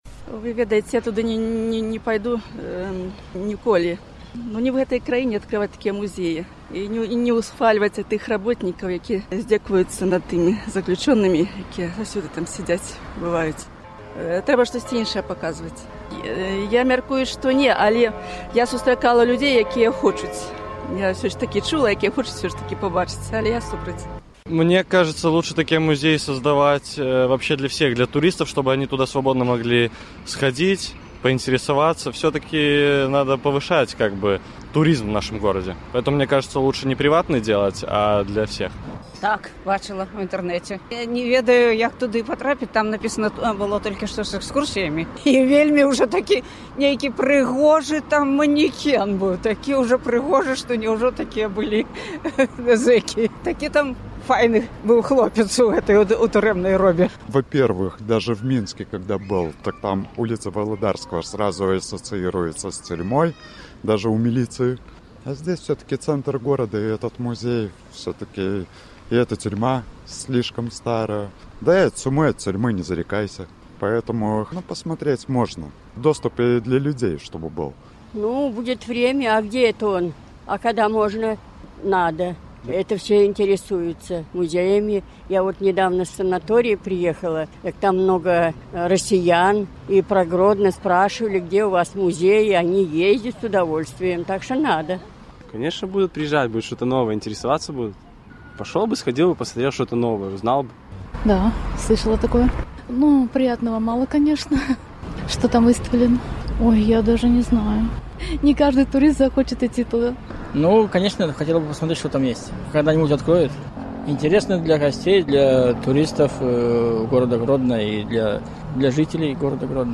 З такімі пытаньнямі мы зьвярнуліся да мінакоў на гарадзенскіх вуліцах.